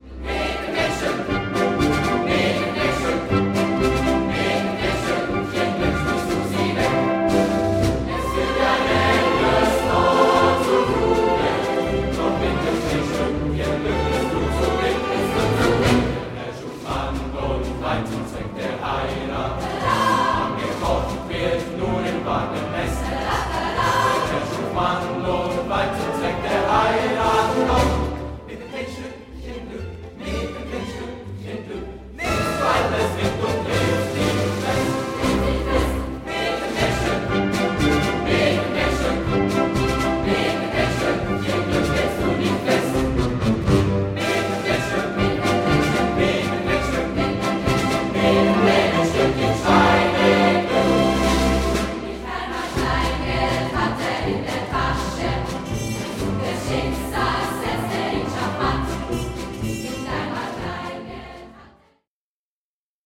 Speziell: passend zur Musical-Orchesterversion